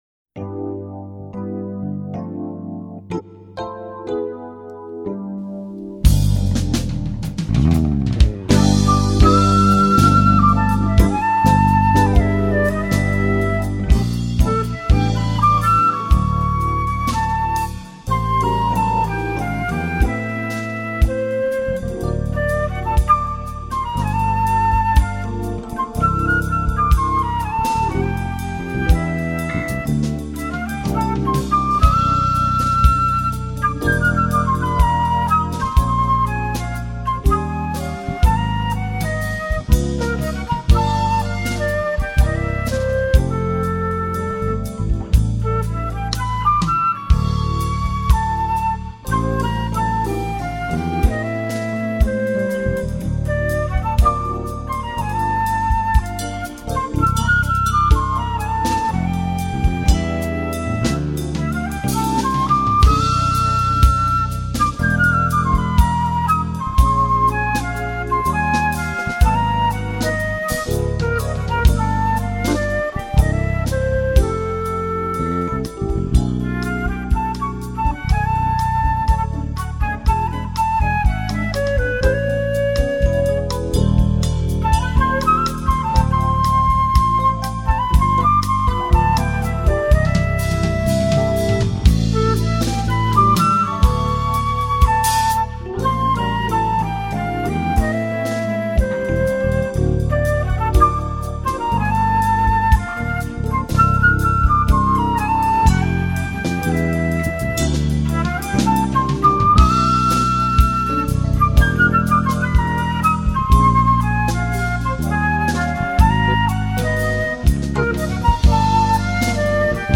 1412   04:52:00   Faixa:     Jazz